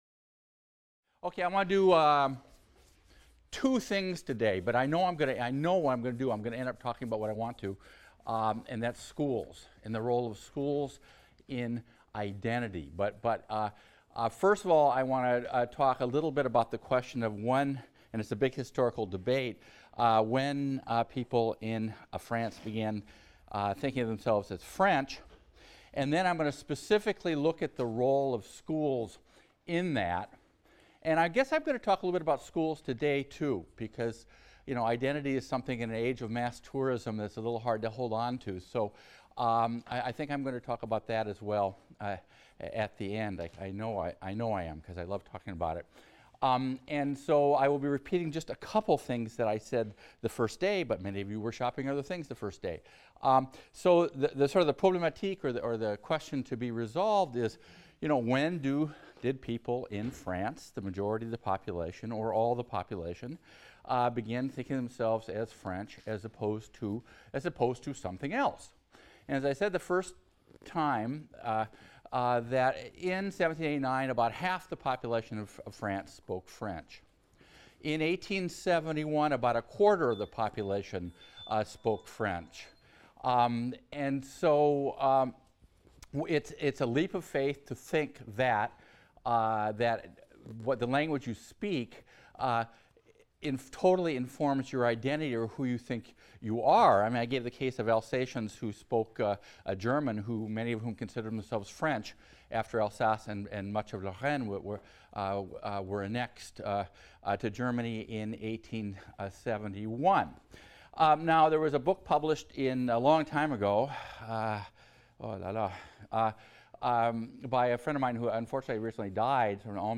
HIST 276 - Lecture 4 - A Nation? Peasants, Language, and French Identity | Open Yale Courses